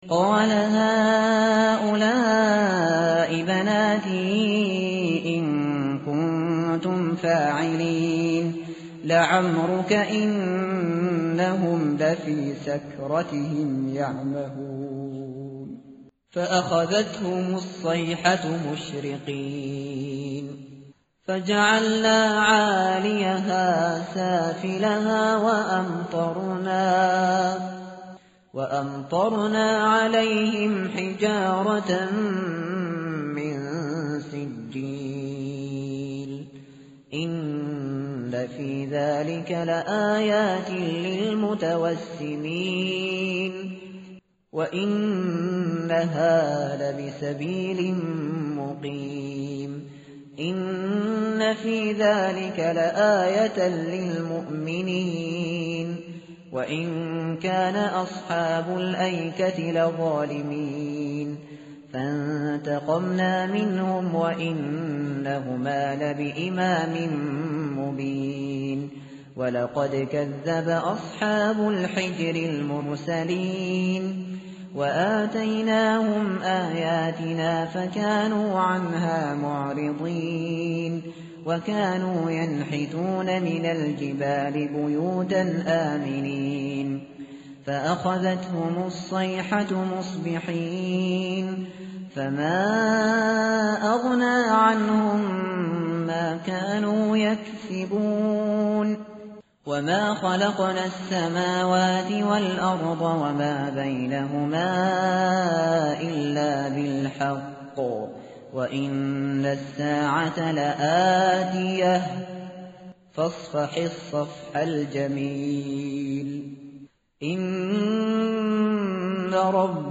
متن قرآن همراه باتلاوت قرآن و ترجمه
tartil_shateri_page_266.mp3